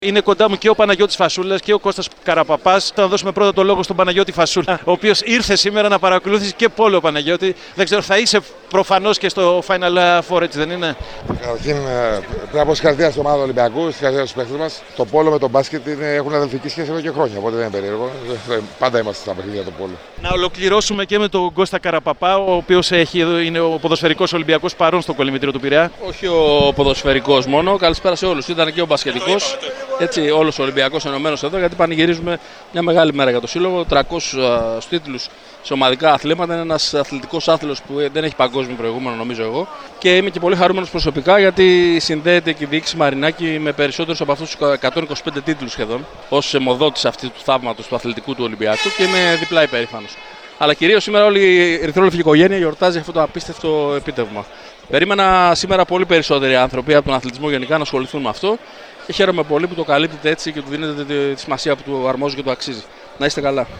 Λίγο μετά τη νίκη-τίτλο των “ερυθρόλευκων” μίλησε στον “αέρα” της ΕΡΑ ΣΠΟΡ, παρέα με τον Παναγιώτη Φασούλα. Αφορμή ήταν η… ιστορικής σημασίας επιτυχία για τον Πειραϊκό σύλλογο, ο οποίος έφτασε τους 300 επίσημους τίτλους στα ομαδικά αθλήματα.